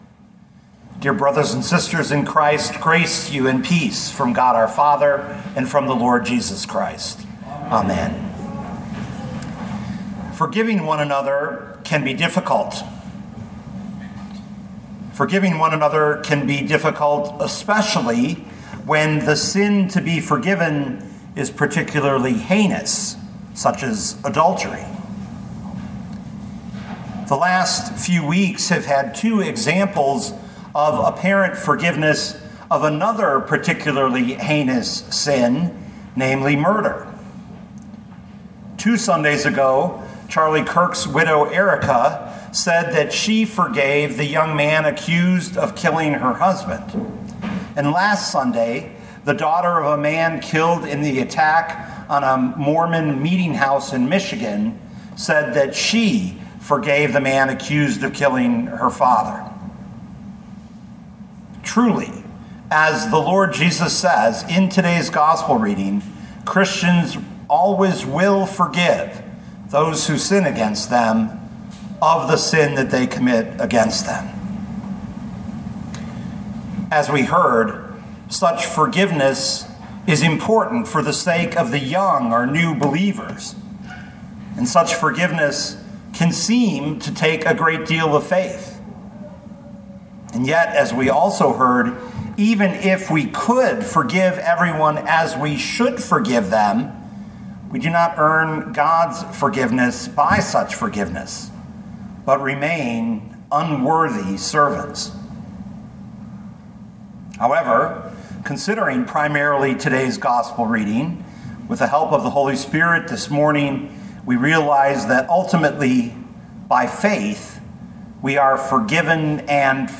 2025 Luke 17:1-10 Listen to the sermon with the player below, or, download the audio.